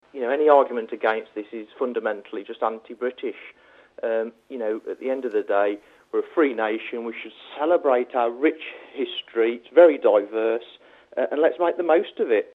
Dudley UKIP Councillor Paul Brothwood tells BBC WM that schools in the borough should fly the union flag and that pupils should sing the national anthem in assemblies.